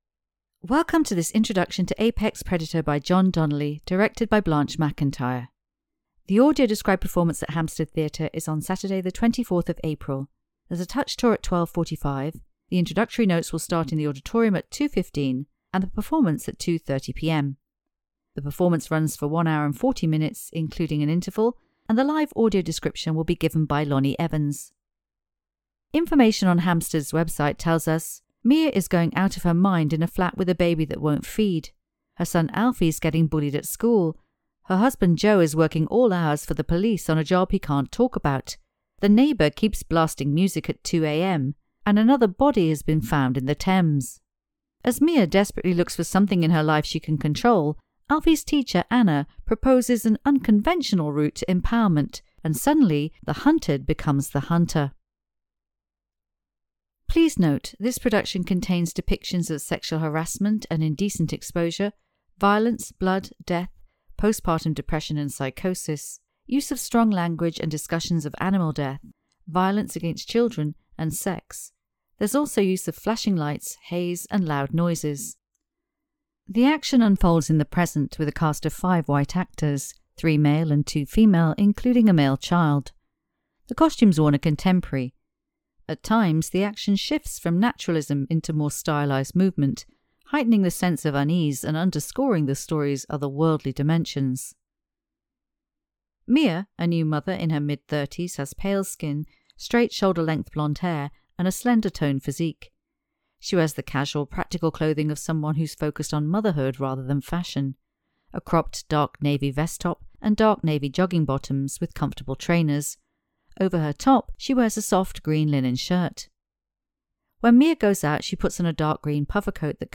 This is a pre-recorded introduction describing the set, characters and costumes.